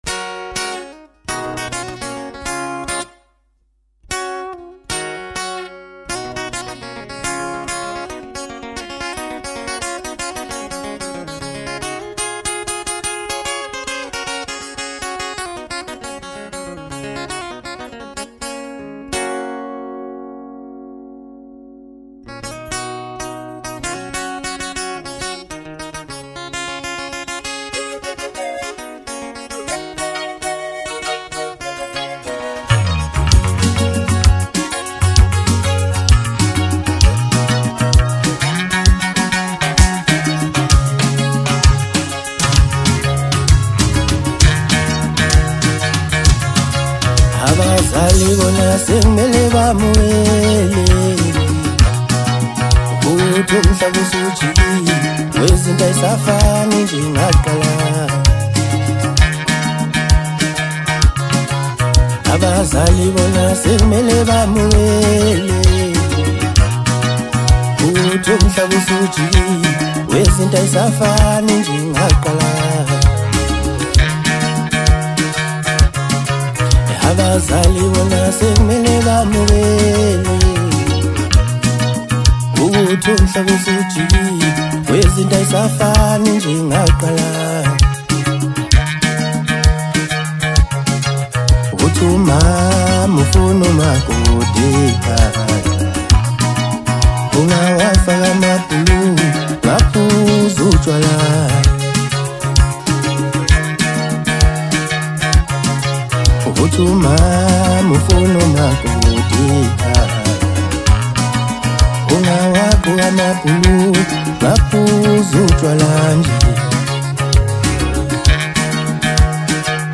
Home » Maskandi
South African Maskandi singer